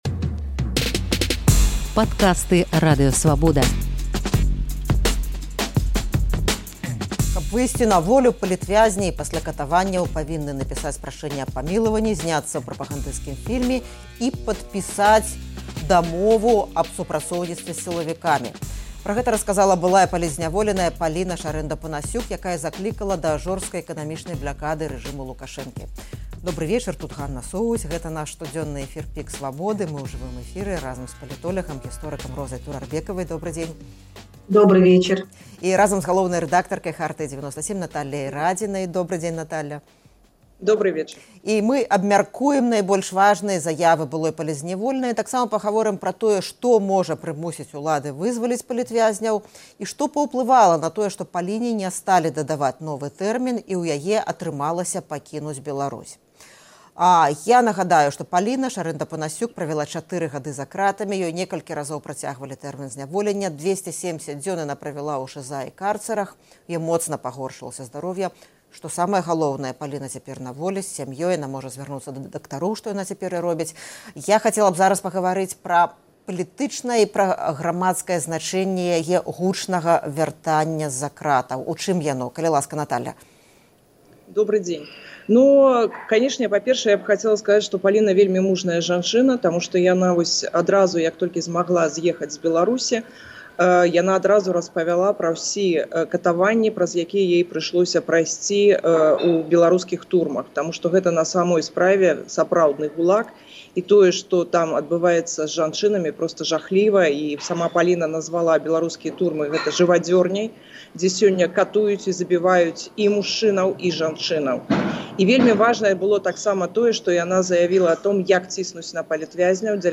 У жывым эфіры «ПіКу Свабоды»